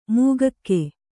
♪ mūgakke